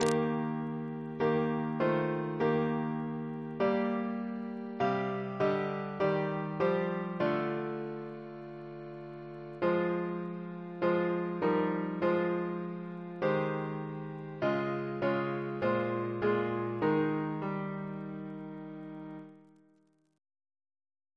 Double chant in E♭ Composer